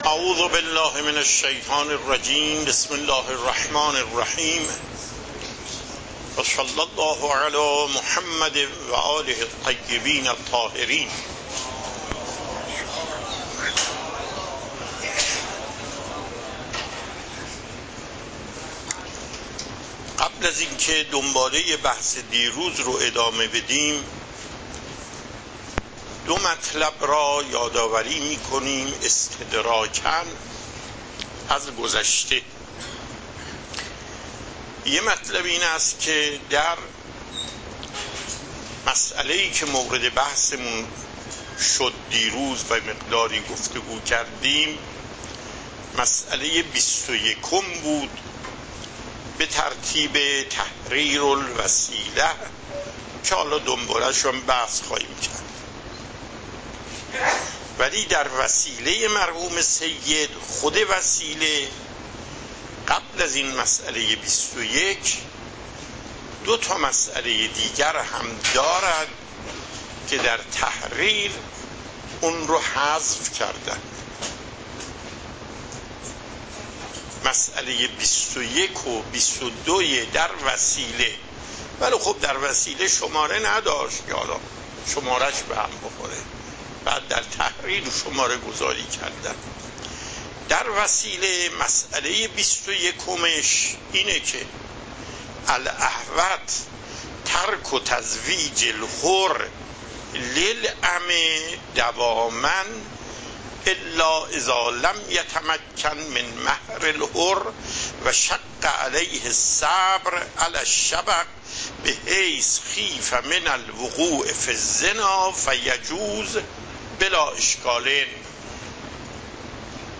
صوت و تقریر درس پخش صوت درس: متن تقریر درس: ↓↓↓ تقریری ثبت نشده است.